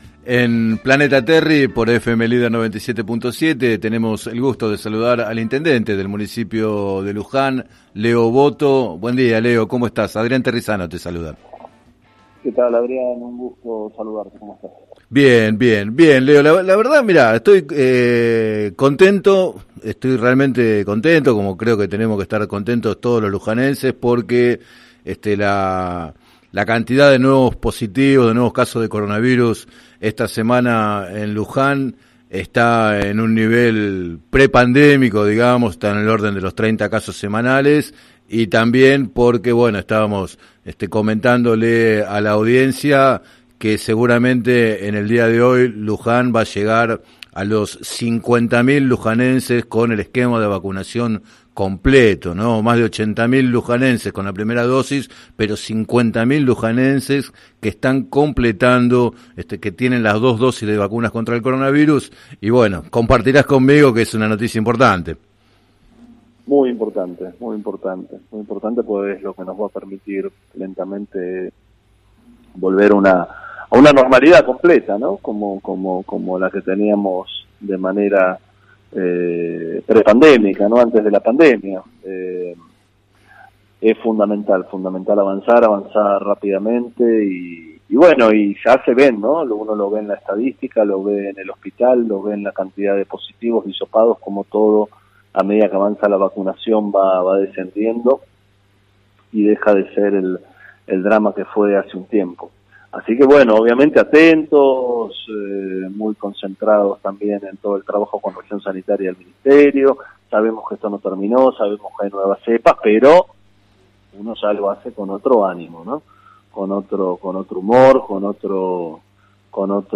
En declaraciones al programa “Planeta Terri” de FM Líder 97.7, el jefe comunal reconoció la posibilidad de una fuga de votos en las primarias hacia la interna de Juntos, que presenta la competencia entre cinco listas, pero instó a acompañar al Frente de Todos.